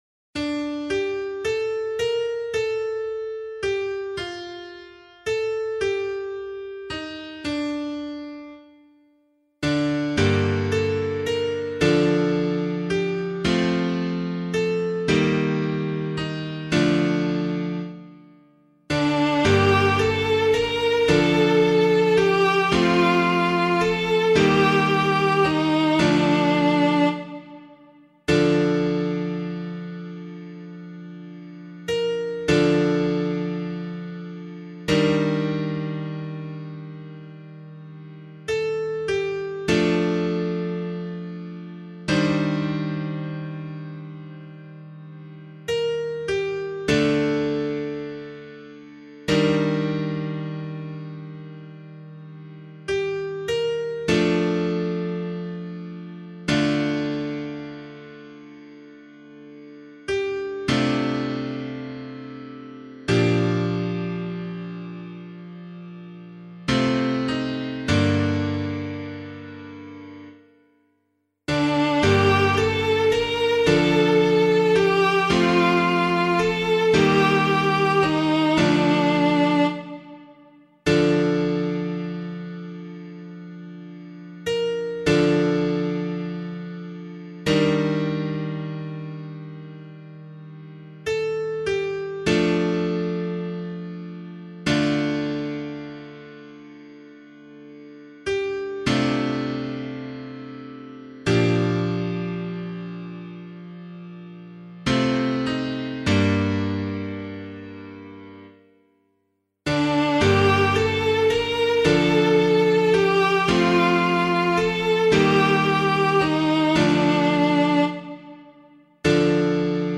012 Ash Wednesday Psalm [APC - LiturgyShare + Meinrad 3] - piano.mp3